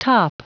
Prononciation du mot top en anglais (fichier audio)